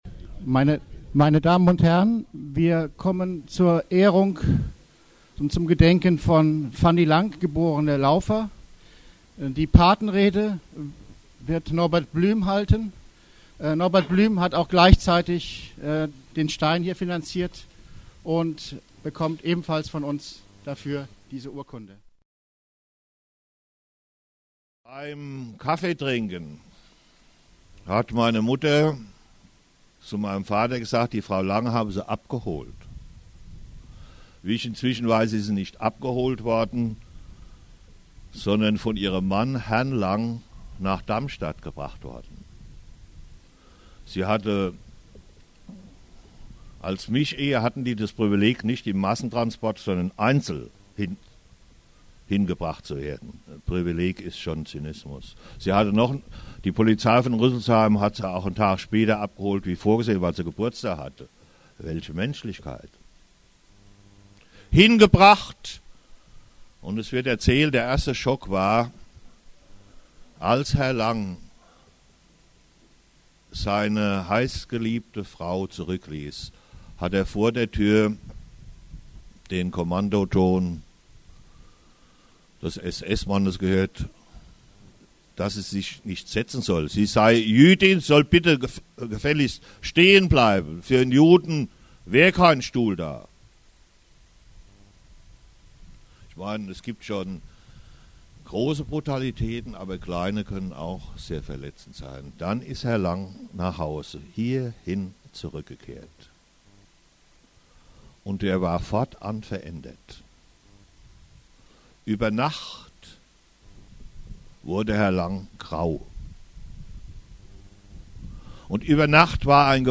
Gedenkrede